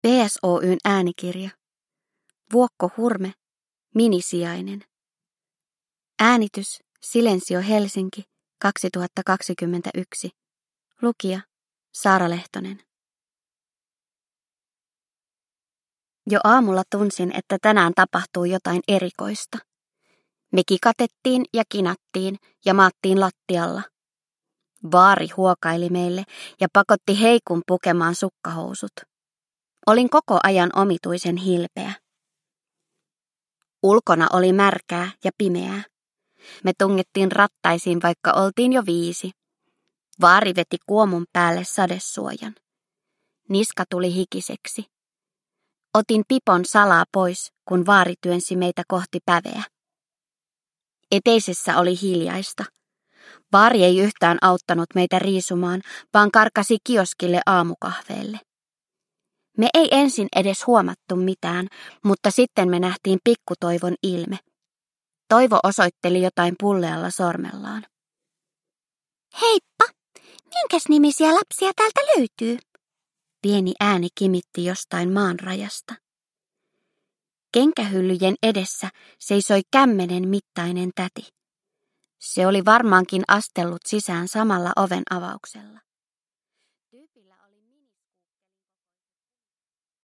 Minisijainen – Ljudbok – Laddas ner